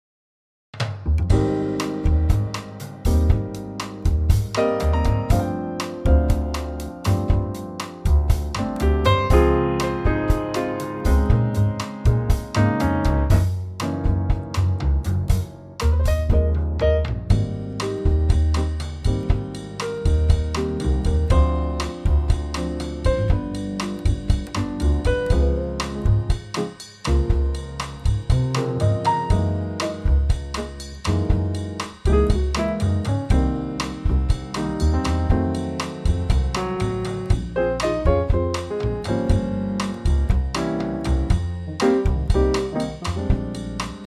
Unique Backing Tracks
key Ab
key - Ab - vocal range - B to Gb (huge range)
Brighter Trio arrangement of this Bossa classic